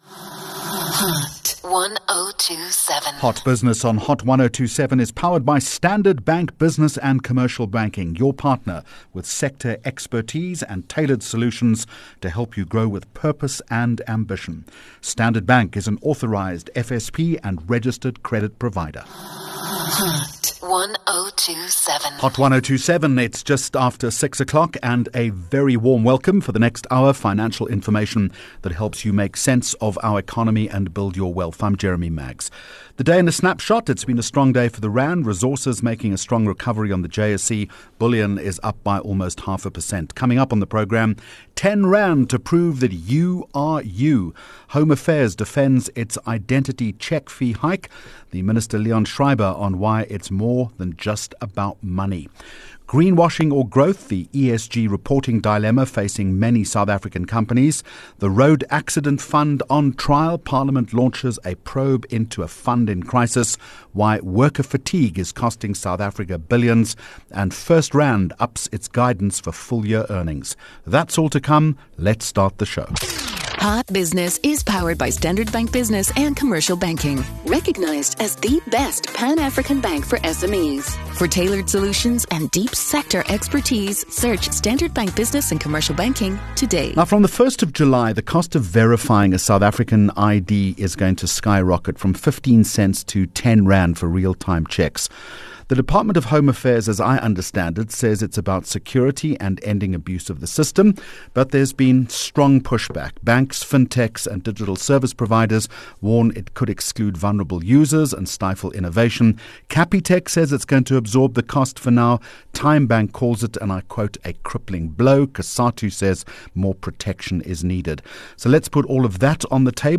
Hot Topic Topic: Home Affairs explains proposed identity verification fee hike Guest: Dr Leon Schreiber -Home Affairs Minister